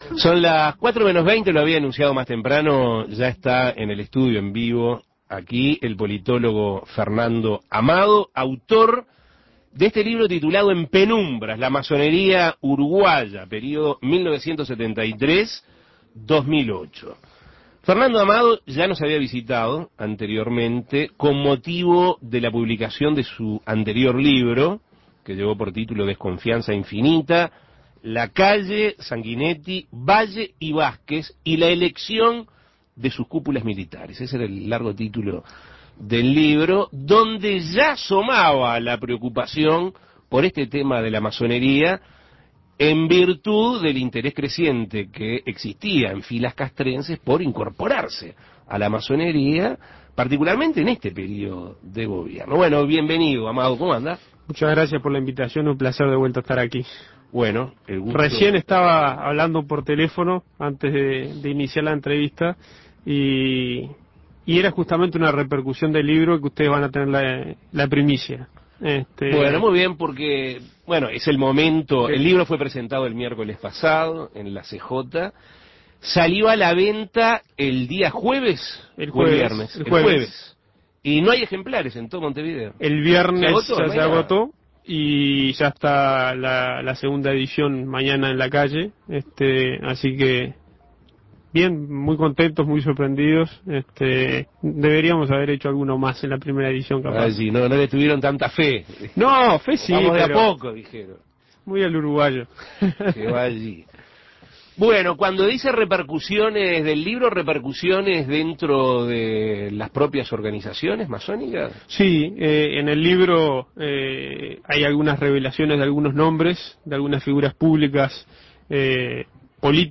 El politólogo Fernando Amado fue entrevistado en Asuntos Pendientes con motivo de la publicación de En penumbras.
Entrevistas